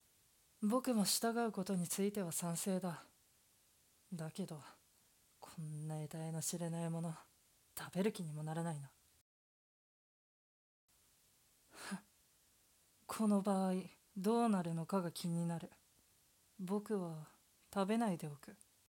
声劇🦈